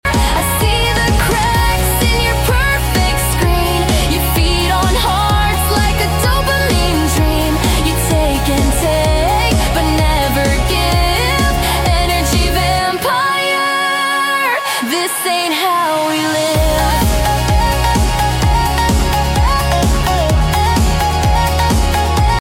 Alternate version, club mix.